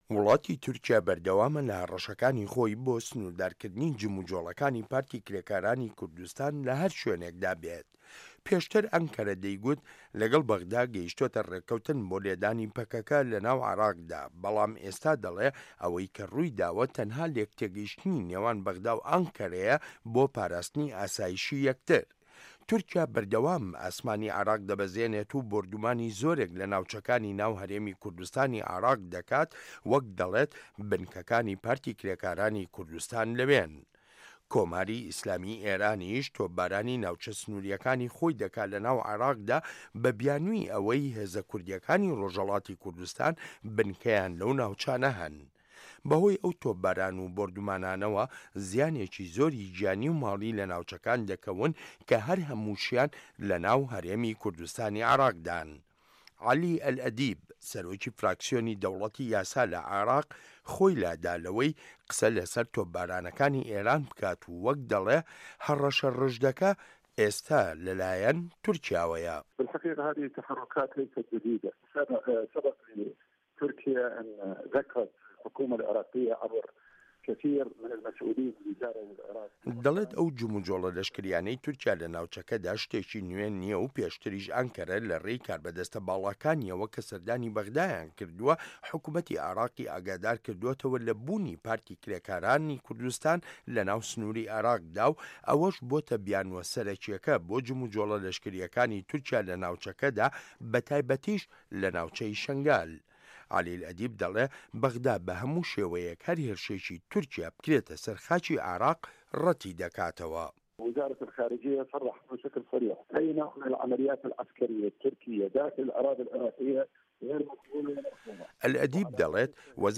ڕاپۆرت لەسەر بنچینەی لێدوانەکانی عەلی ئەلئەدیب